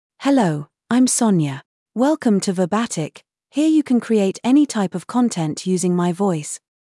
Sonia — Female English (United Kingdom) AI Voice | TTS, Voice Cloning & Video | Verbatik AI
Sonia is a female AI voice for English (United Kingdom).
Voice sample
Listen to Sonia's female English voice.
Sonia delivers clear pronunciation with authentic United Kingdom English intonation, making your content sound professionally produced.